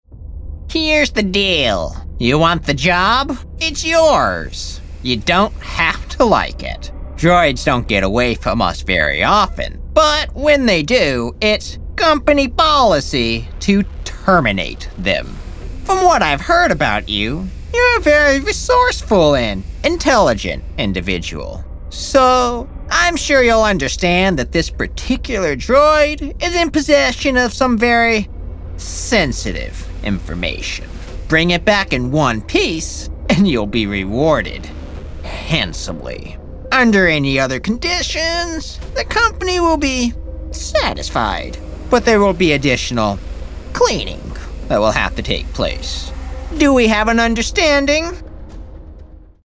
Samples that reveal my full range: intimate storytelling, rich narration, and bold character voices.
Character Demo
Sci-fi.mp3